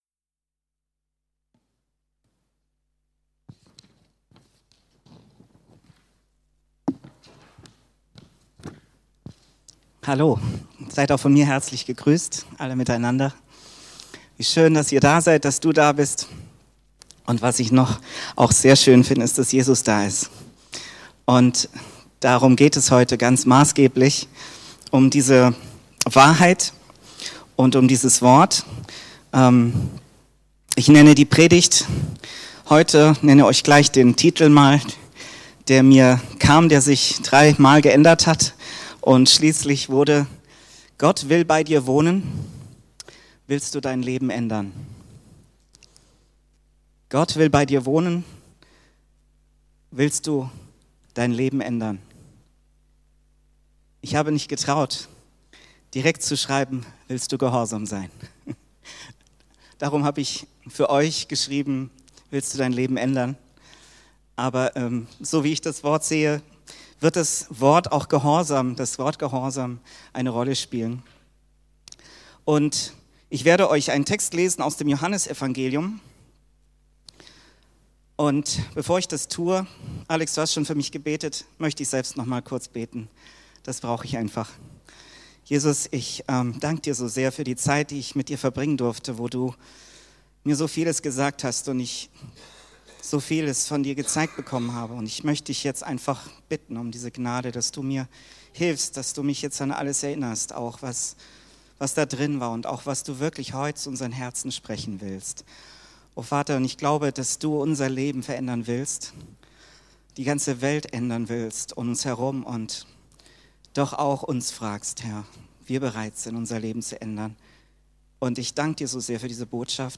Er sucht Beziehung zu uns und bringt in dieser Nähe seine Liebe zu uns zum Ausdruck. In seiner Predigt vom 4.